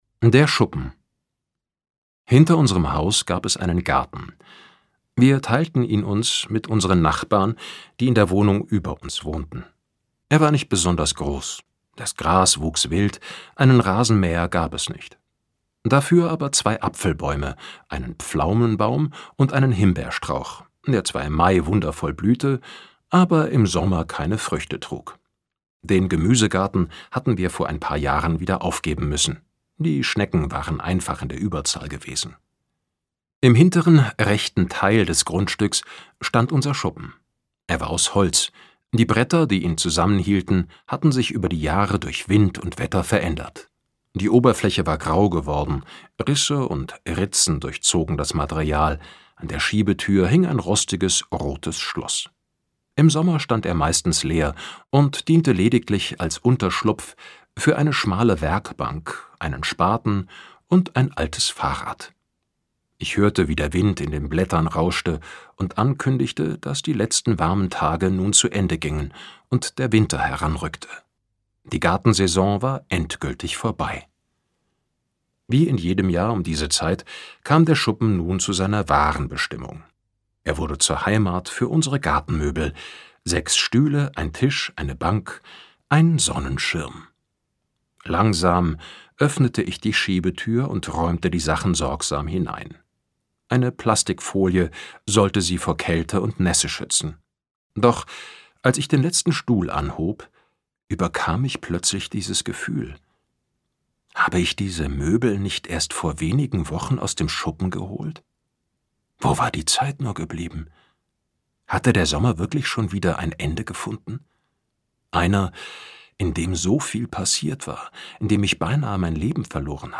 Sprecher Götz Otto